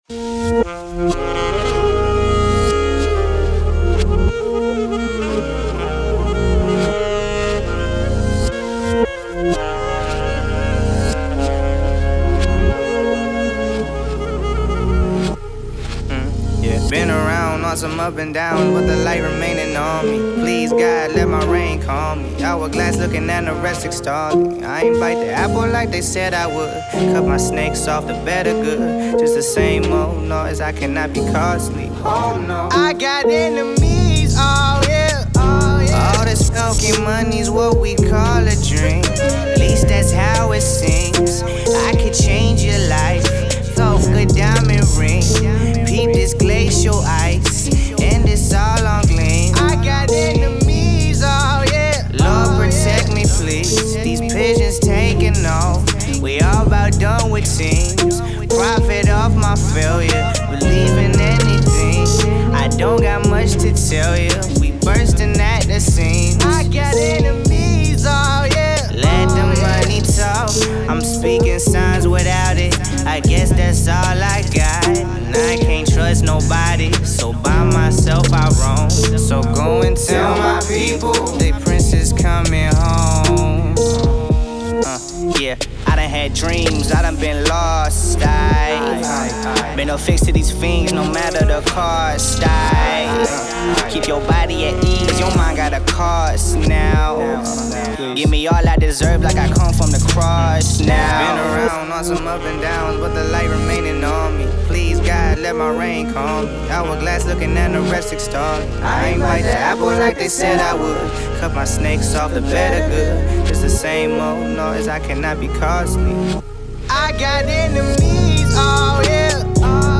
Hiphop
smooth new single